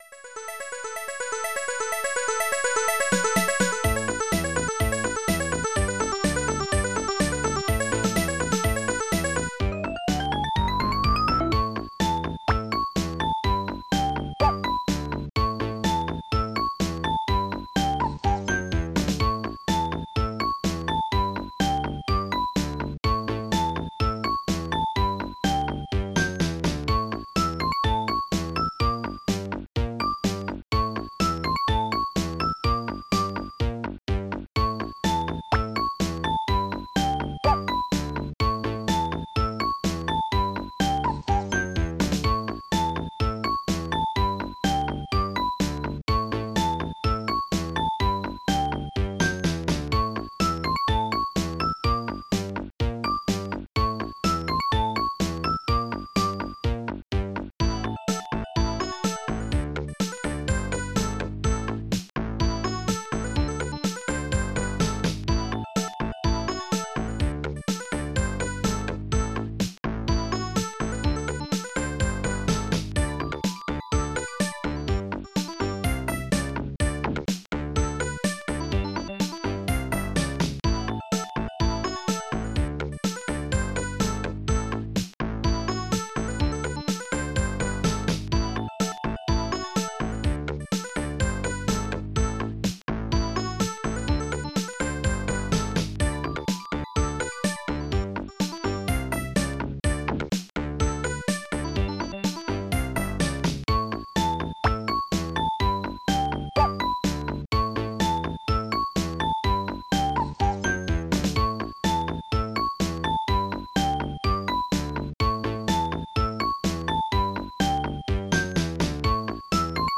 Advanced Module Format Module
Instruments 2_snare 2_bassdrum dragonsynth1 EloPin jahrmarkt1 Sploit synth38 cuicah koko cymbol-444